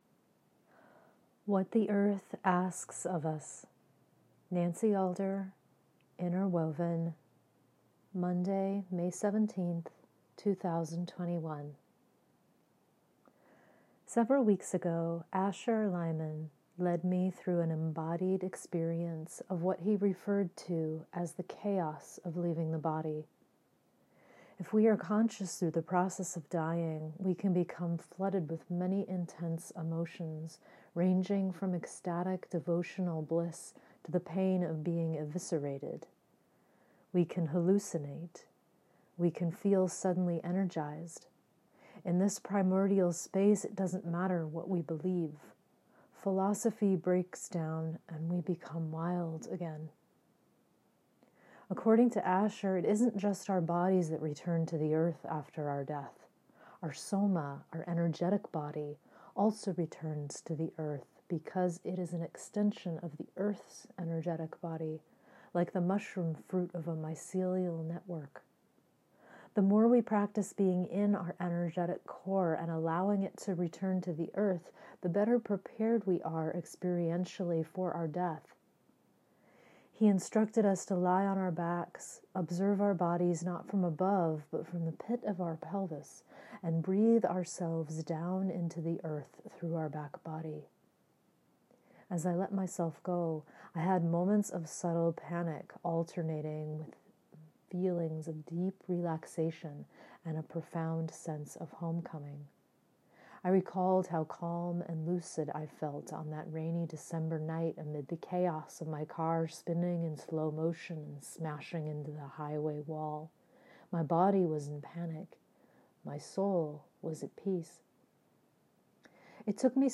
Enjoy this 10-minute read or let me read it to you via the audio recording at the top of the post on my website.